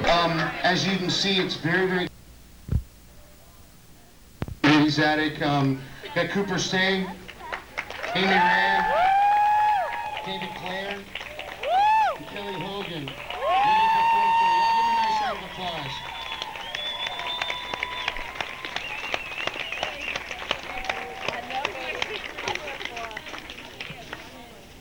songwriter showcase - eddie's attic - decatur, georgia
(acoustic show)
02. introduction (0:24)